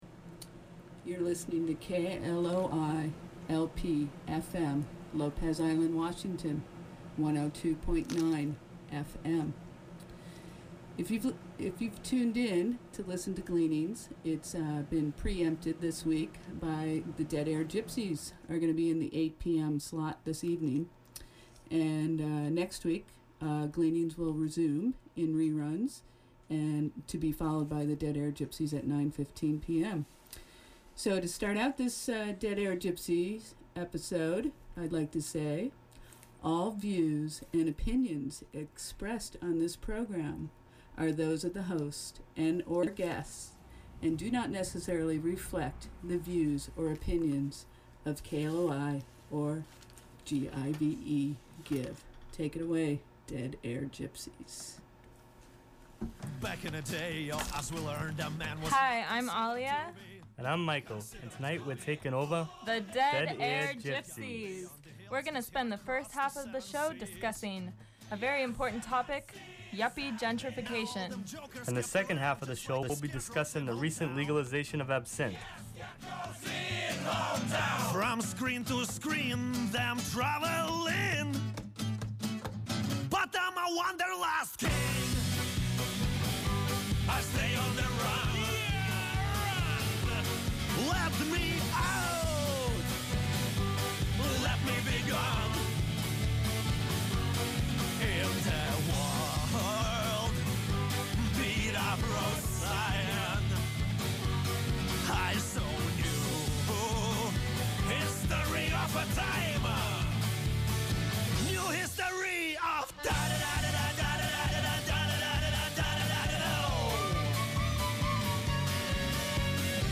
” Live theatre. Call in show. Social commentary mixed with great music.